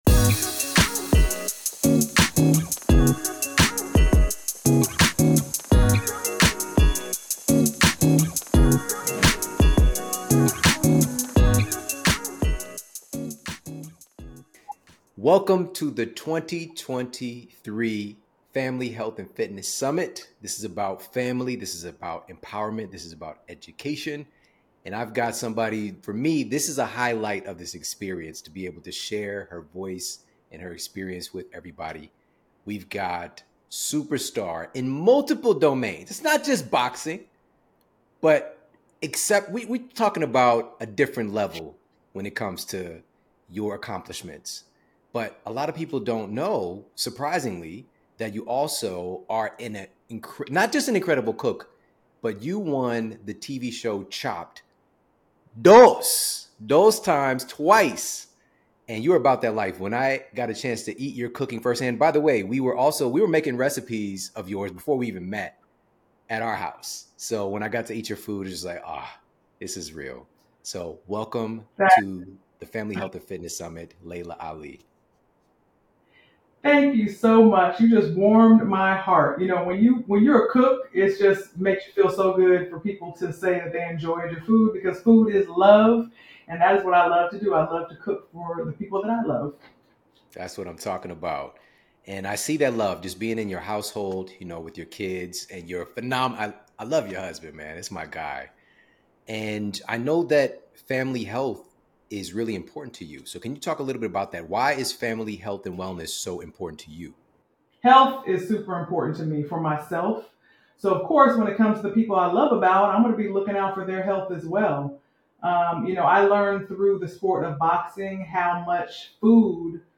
Laila Ali is a world-class athlete, fitness and wellness advocate, TV host, home chef, founder of the Laila Ali Lifestyle Brand, and mother of two.
LailaAliSummitInterviewAudio.mp3